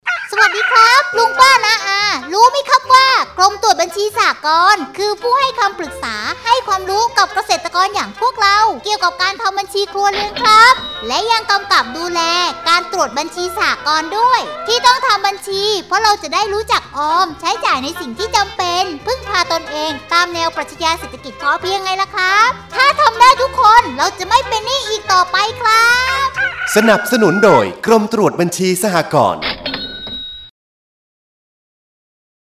สปอตวิทยุ ส่งเสริมการทำบัญชี แบบที่ 2